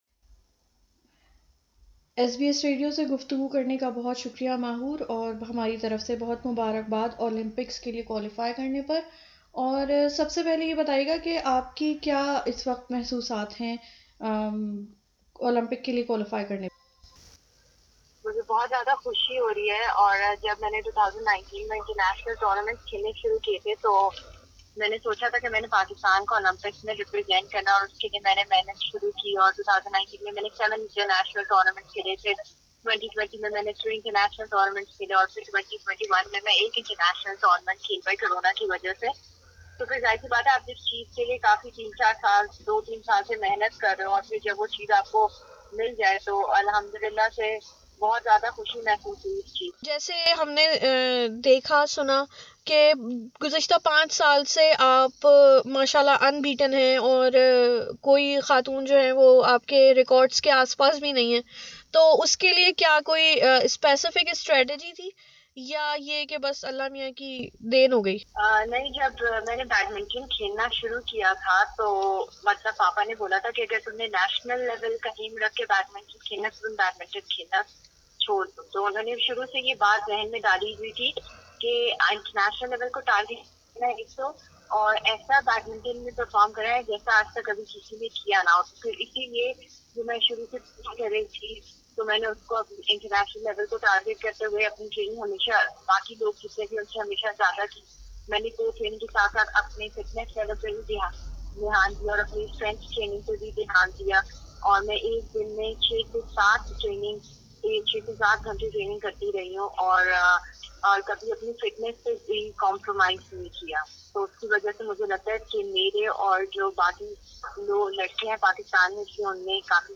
ایس بی ایس اردو سے بات کرتے ہوئے ماحورشہزاد نے بتایا کہ بچپن سے ہی والد نے بھرپور ساتھ دیا اور بیڈمیںٹن کھیلنے میں مدد کی-